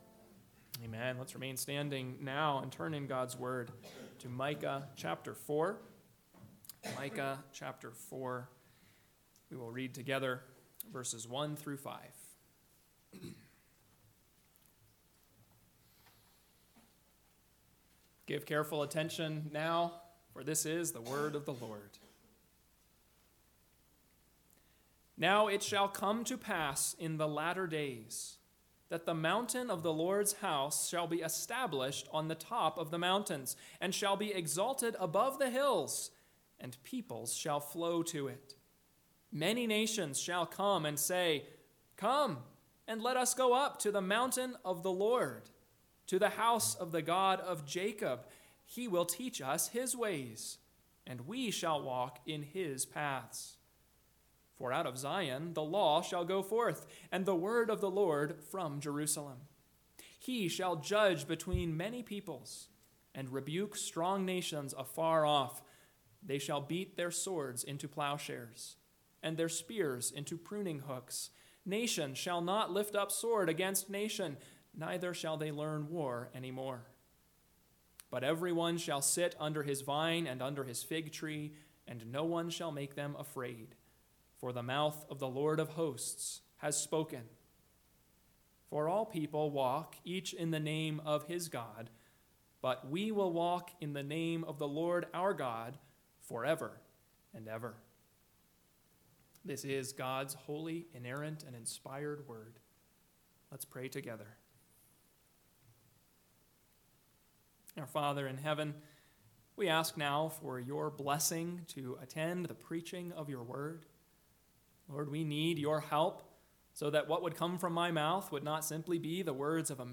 PM Sermon – 10/13/2024 – Micah 4:1-5 – Northwoods Sermons